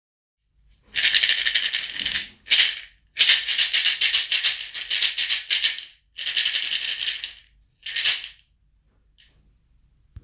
バオバブの実シェイカー大
バオバブを振ると、中の種がカラカラ、やさしい音のシェイカーです。
素材： バオバブの実